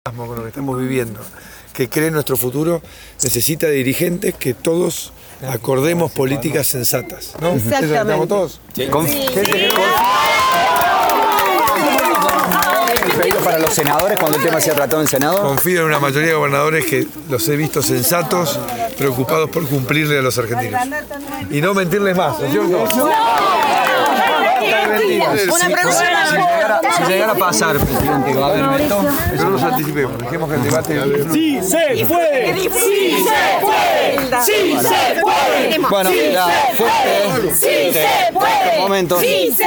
Alrededor de las 18:30 hs de este viernes, el presidente de la nación Mauricio Macri salió a la puerta del country Potrerillo de Larreta a saludar a los militantes del Pro que se habían apostado en el lugar.
Intercambió palabras con algunos de ellos: «necesitamos dirigentes con los que podamos acordar políticas sensatas. Confío en la mayoría de los gobernadores porque los he visto sensatos y preocupados por cumplirle a los argentinos, y no mentirles más», señaló mientras los presentes coreaban «sí se puede».